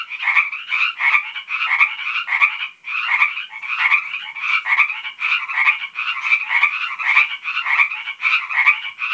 these little guys.
frogs.m4a